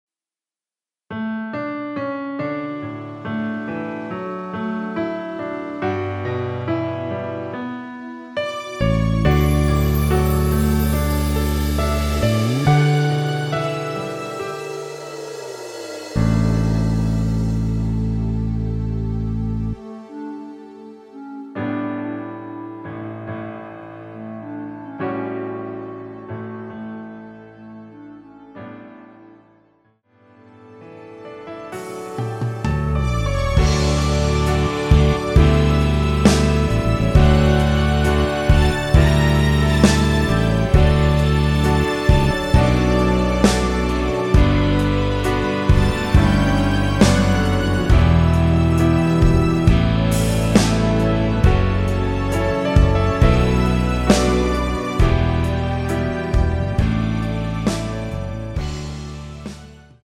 ◈ 곡명 옆 (-1)은 반음 내림, (+1)은 반음 올림 입니다.
멜로디 MR이라고 합니다.
앞부분30초, 뒷부분30초씩 편집해서 올려 드리고 있습니다.
위처럼 미리듣기를 만들어서 그렇습니다.